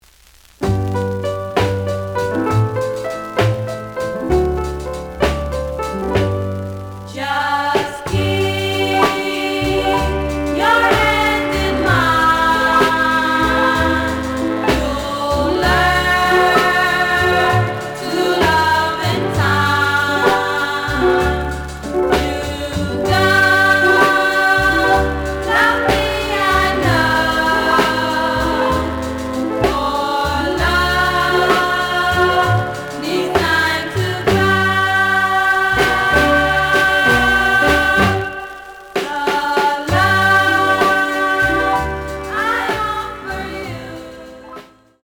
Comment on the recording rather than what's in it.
The audio sample is recorded from the actual item. Looks good, but slight noise on B side.